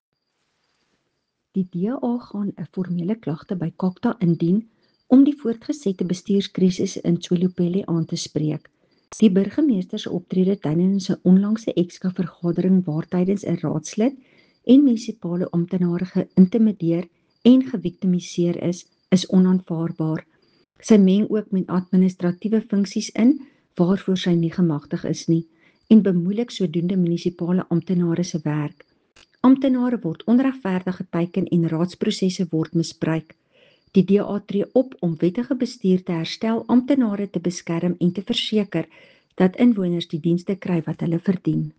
Afrikaans soundbites by Cllr Estelle Pretorius and Sesotho soundbite by David Masoeu MPL.